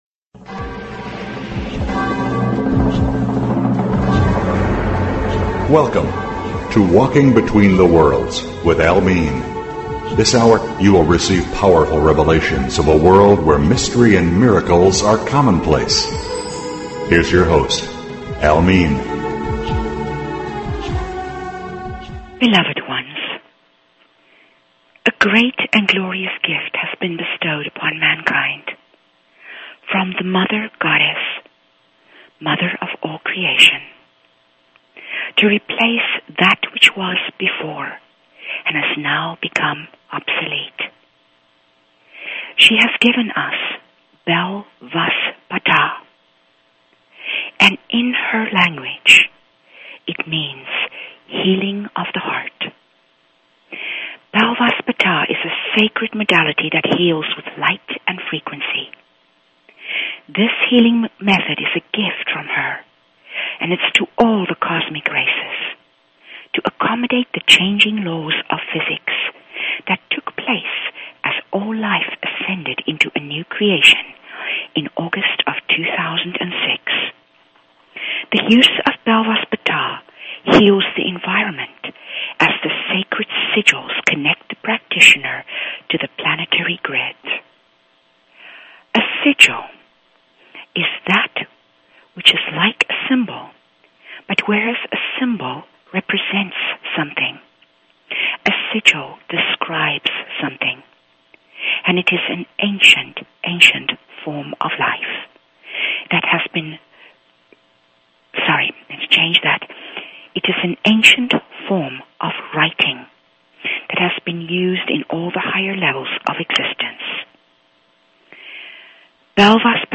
Talk Show Episode, Audio Podcast, Opening_the_Doors_of_Heaven and Courtesy of BBS Radio on , show guests , about , categorized as